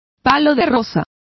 Complete with pronunciation of the translation of rosewood.